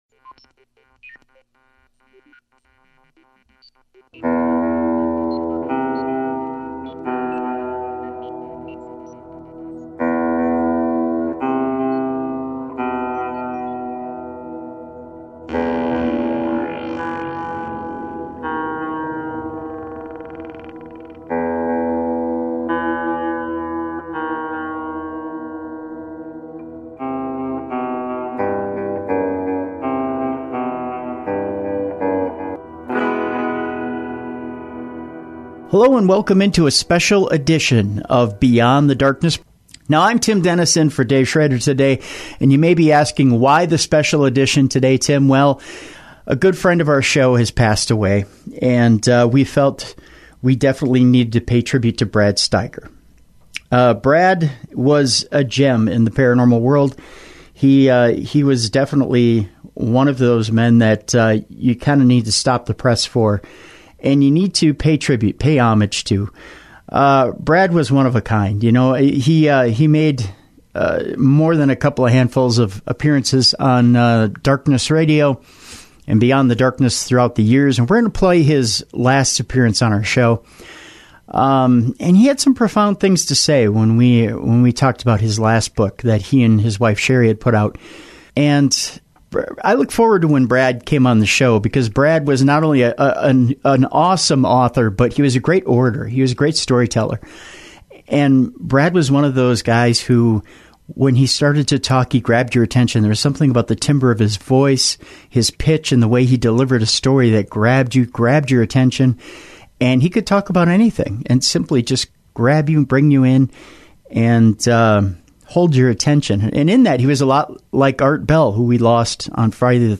In honor of Brad's illustrious career, we are airing his last appearance with us from 2016. We talk about Real Voices, Voices From Beyond, & Parallel Dimensions with Brad one last time.